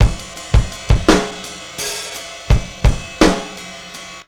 Sealbeat 85bpm.wav